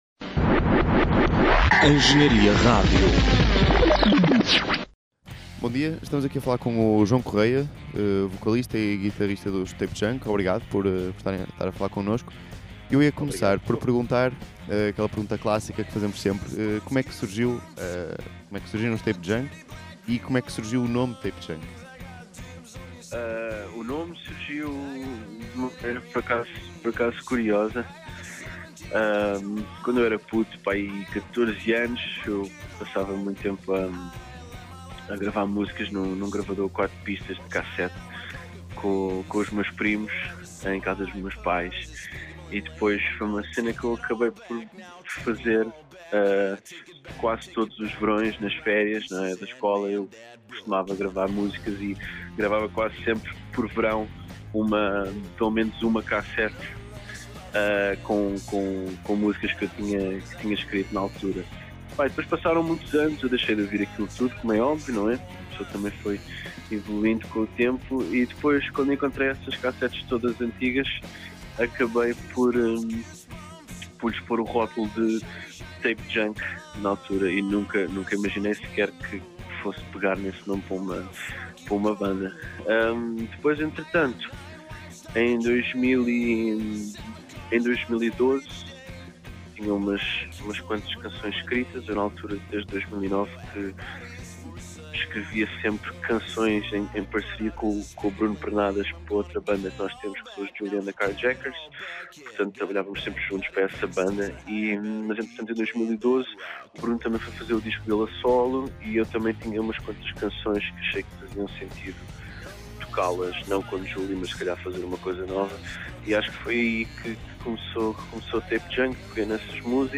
Entrevista Tape Junk - Engenharia Rádio
tape-junk-entrevista.mp3